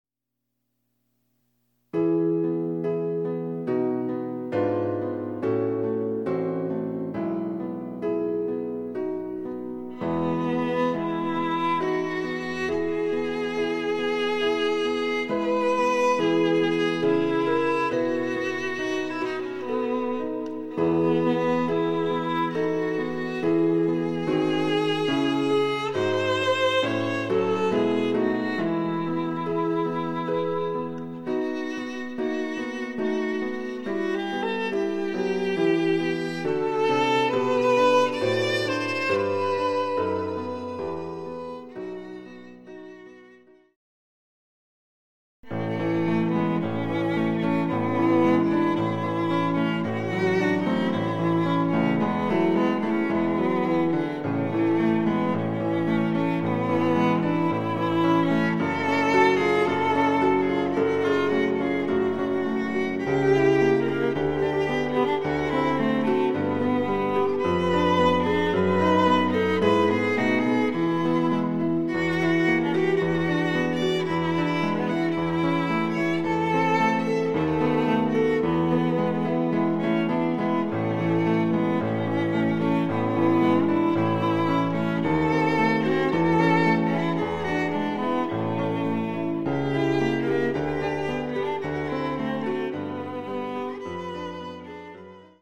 ■ヴィオラによる演奏
ピアノ（電子楽器）